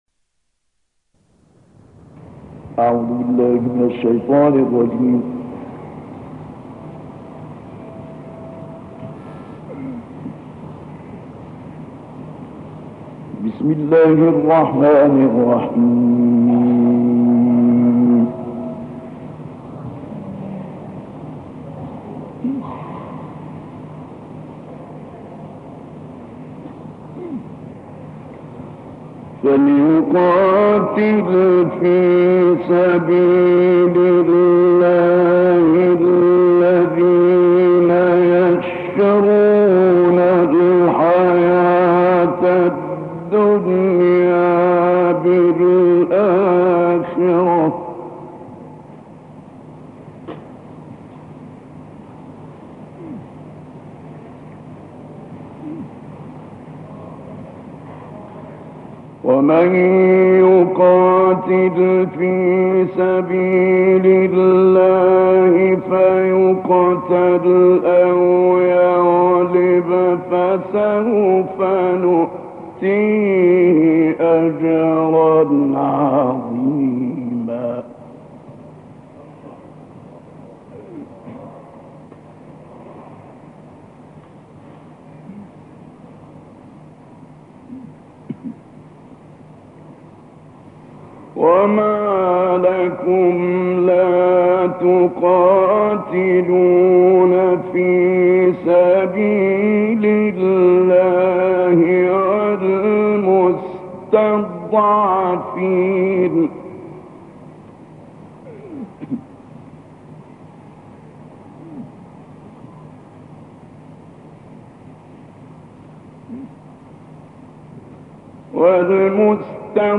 تلاوت
مدت زمان این تلاوت معناگرایانه و تکنیکی 26 دقیقه است.